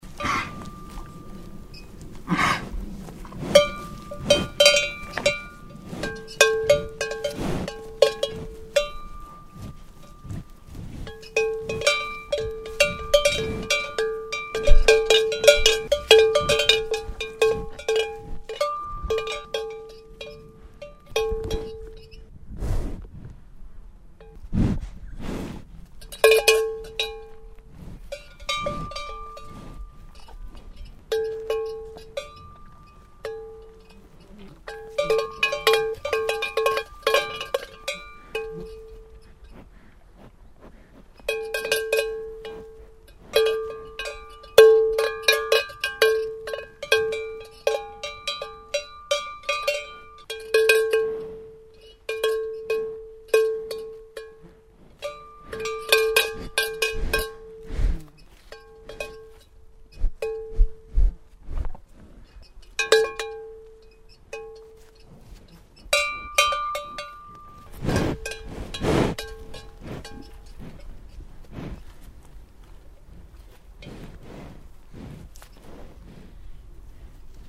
Ein hörenswertes Interview mit einem grossen tier. Aufgenommen im Jahr 2003 in den Bergen bei Immenstadt. Wer da das Gras nicht wachsen hört, hört zumindest die Glocken läuten!